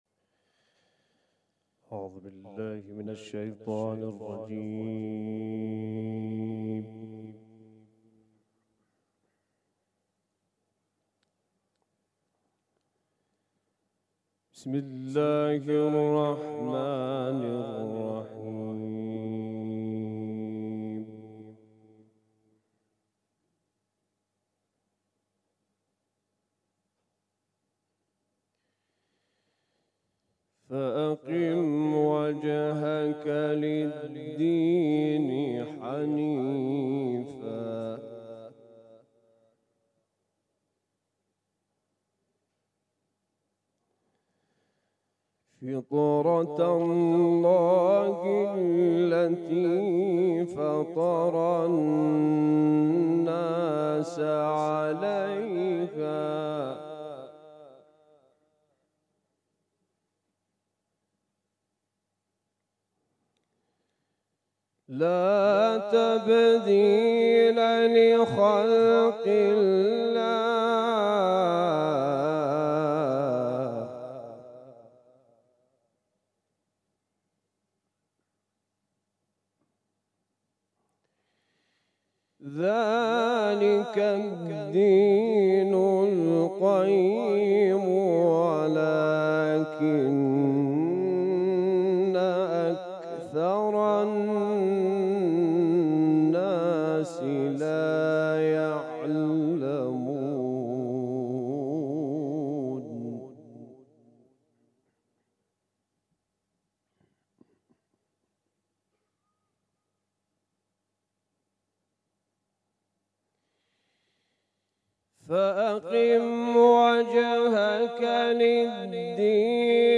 در فینال چهل و یکمین دوره مسابقات سراسری قرآن کریم رشته قرائت